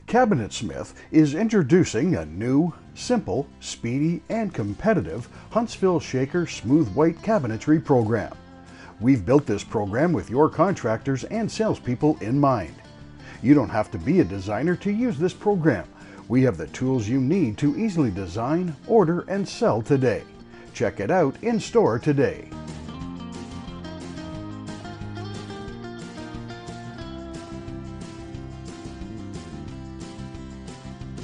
Take Hold Message